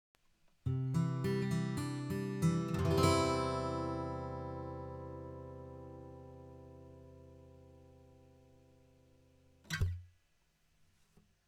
アコースティックギター
まずはアコースティックギターです。モノラルで録るとこんな感じです。今回は指板とホールのちょうど間を狙ってみました。
モノラル